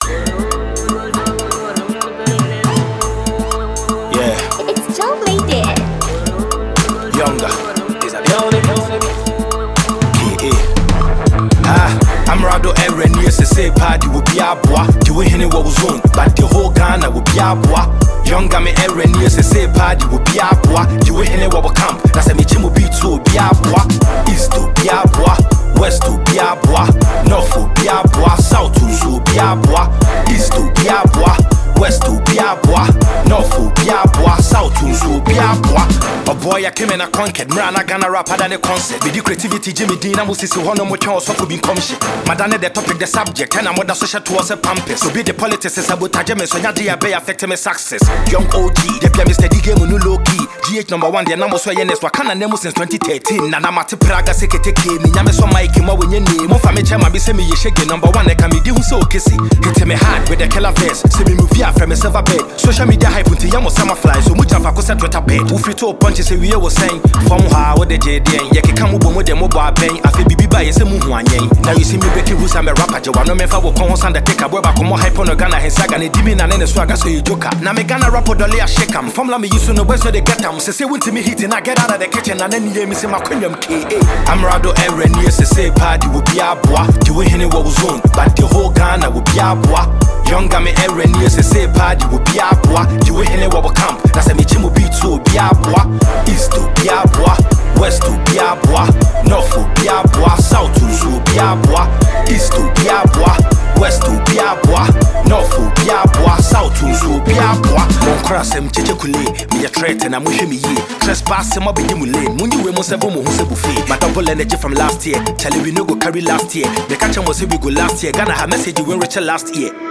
and this is a rap mp3 download for all.